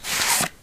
cardTakeOutPackage2.ogg